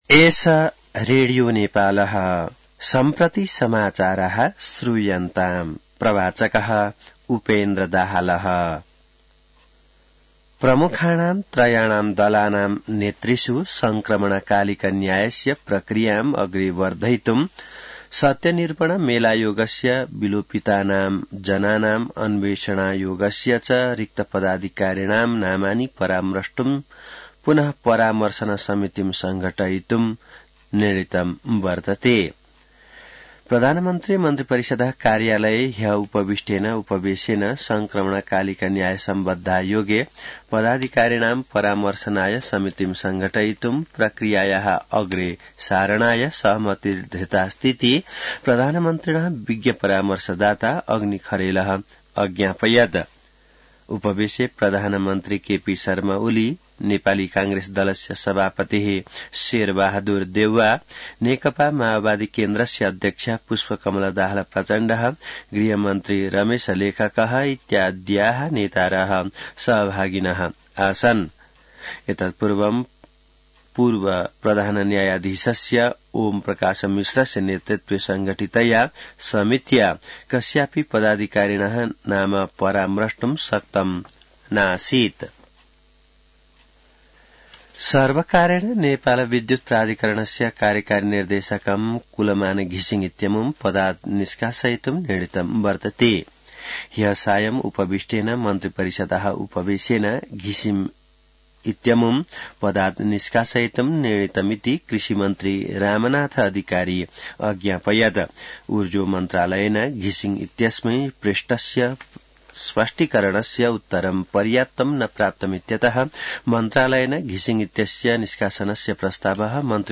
संस्कृत समाचार : १२ चैत , २०८१